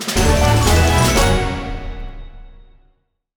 Victory.wav